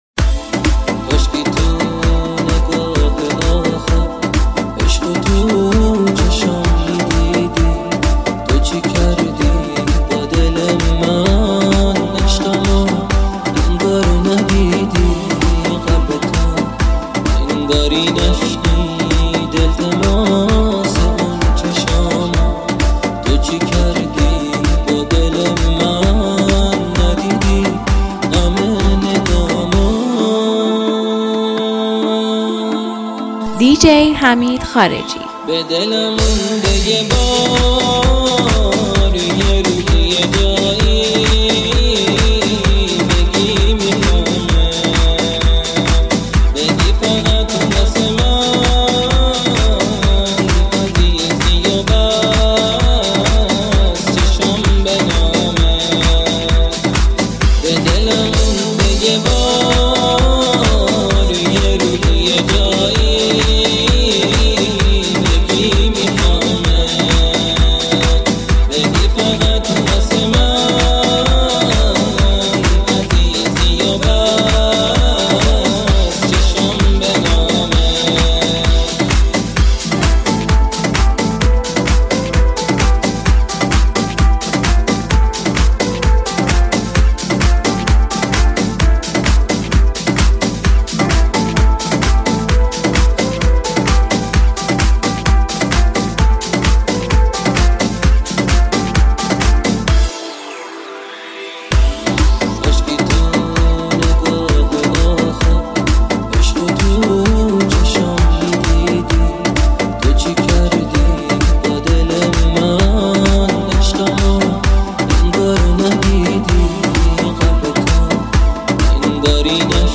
(بیس دار)